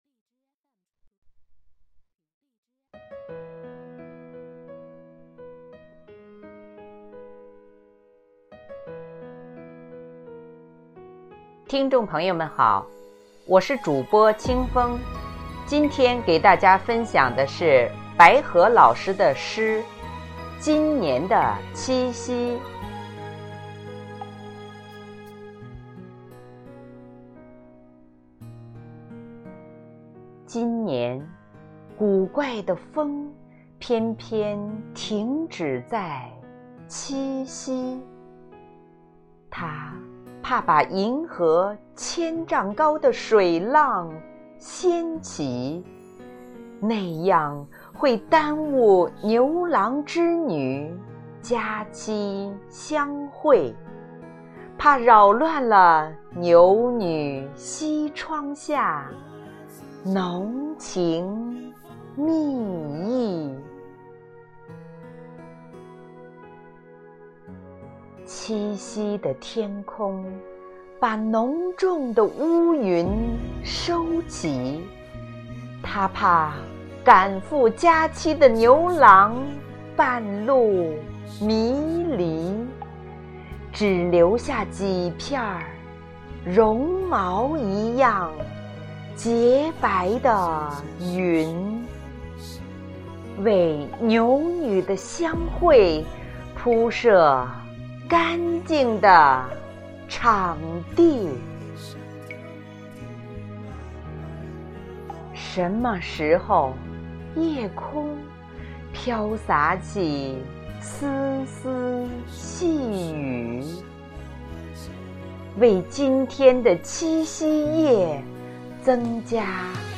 主播